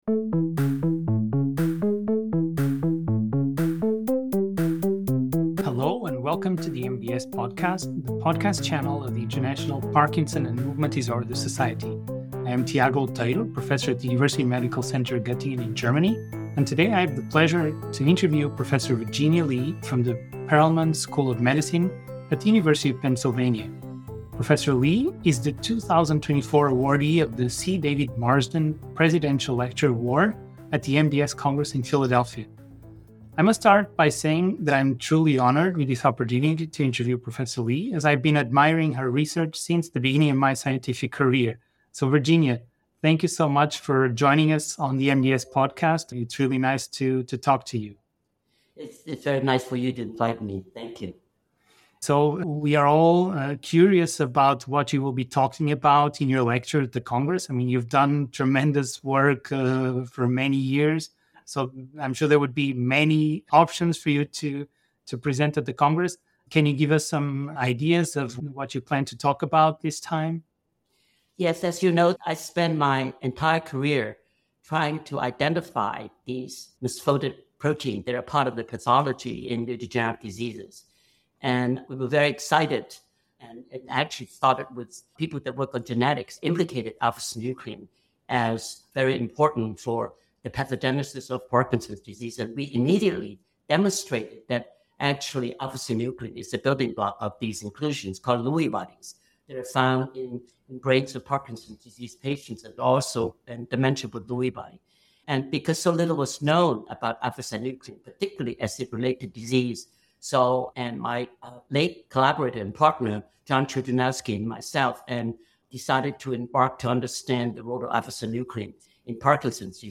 She discusses her journey into the field and the critical role of alpha-synuclein in Parkinson's. The conversation highlights advancements and challenges in the study of protein aggregation and misfolding.